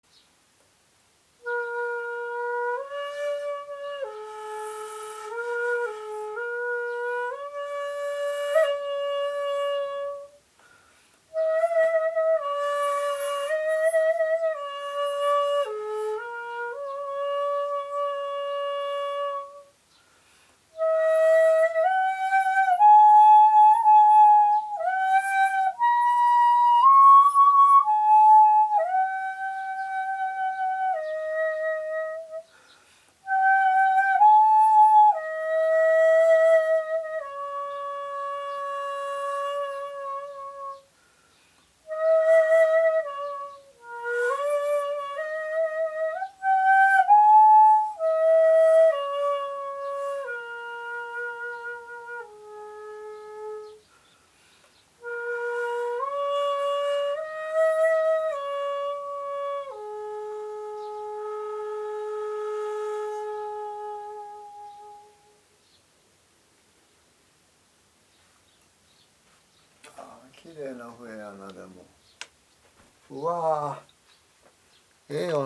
一節切の音色を追求しますとやはり黒竹よりは真竹で、できれば年数の経った煤竹が最高のようです。
最近仕上がりました煤竹復元一節切の音色で「吉野山」をお聴きください。